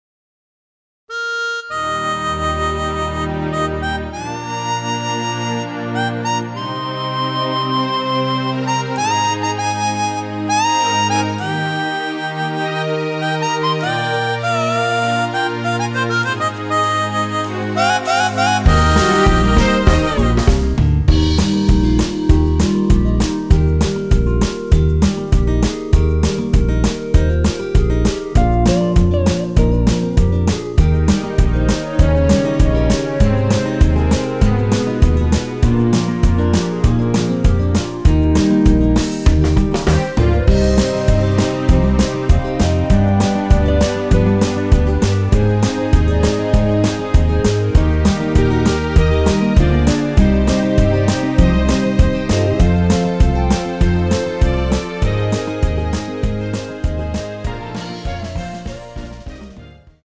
전주가 길어서 8마디로 편곡 하였으며
원키에서(+3)올린 (1절+후렴)으로 진행되는 멜로디 포함된 MR입니다.
Eb
앞부분30초, 뒷부분30초씩 편집해서 올려 드리고 있습니다.
중간에 음이 끈어지고 다시 나오는 이유는